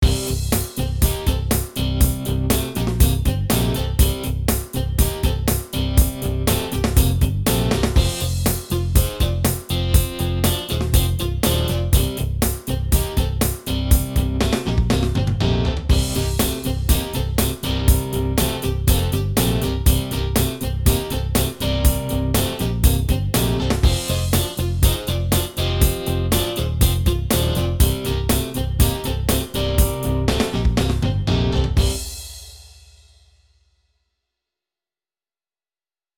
I uploaded this basic example a few days back to show KARMA playing like an arranger keyboard Style but with the added bonus that it's also able to do things an arranger can't like add a certain amount of randomness to the pattern.
There's nothing really locked down to a set pattern like arranger keyboards are. Everything is being generated in real-time and if you listen to the drums for example you will hear them play more naturally just like real drummer will.
That's not me activating fills or doing anything other than playing 2 different chords. Even the main variation change you hear is generated in real-time by KARMA.